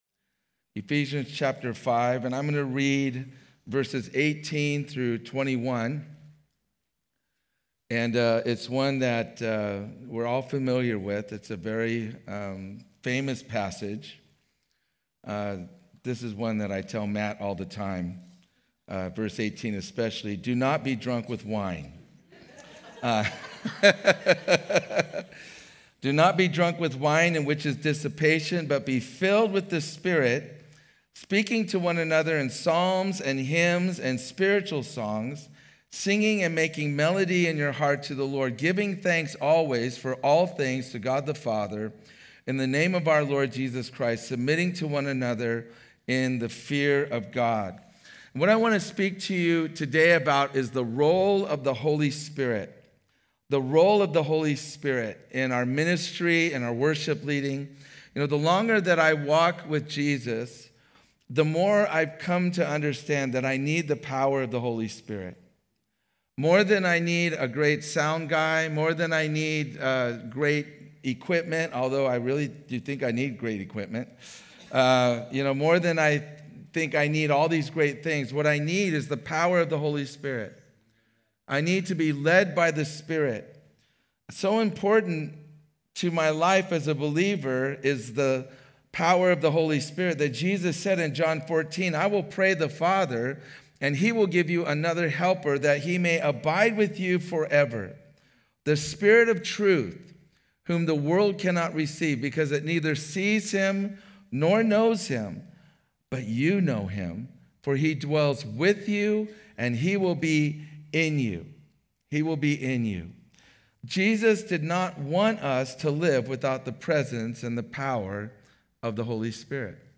Home » Sermons » Presence and Power
Conference: Worship Conference